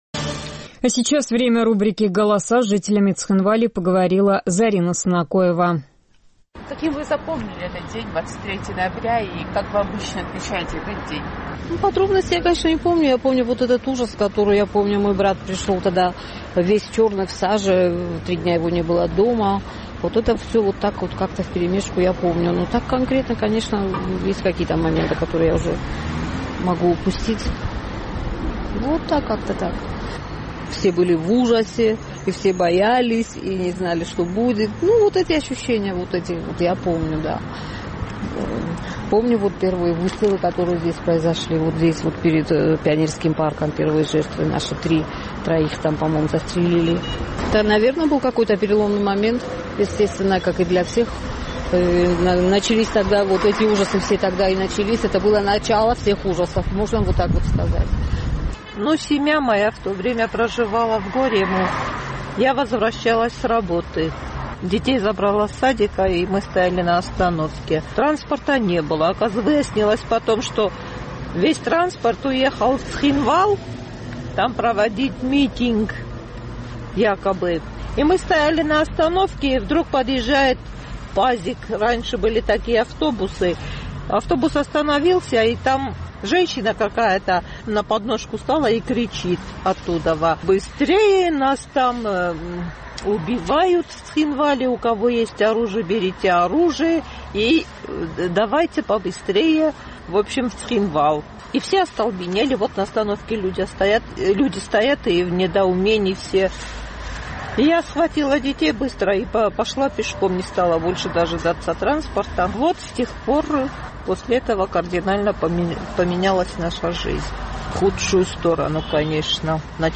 Наш цхинвальский корреспондент спросила у местных жителей, каким они запомнили этот день и что он для них значит.